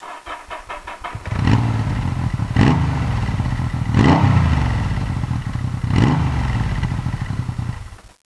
Vous avez déjà écouté le son d'une XS ? Non !? Voilà une lacune réparée, avec un petit
démarrage à télécharger (364Ko)...